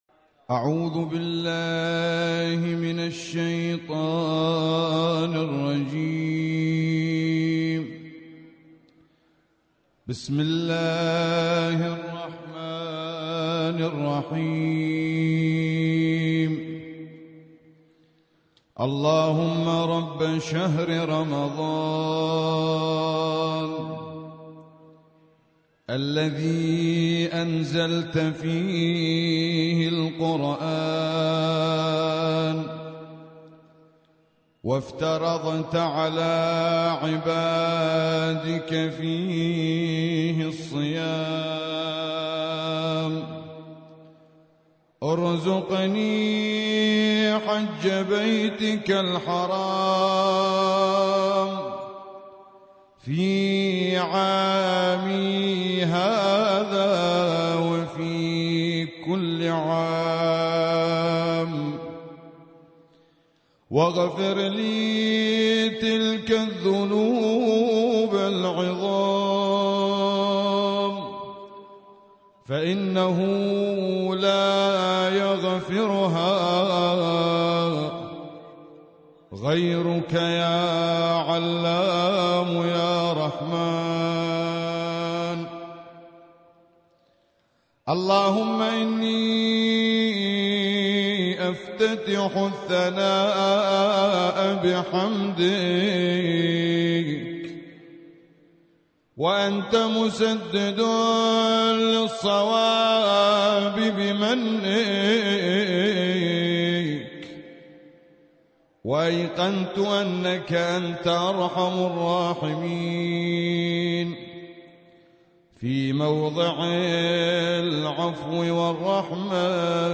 دعاء
الرادود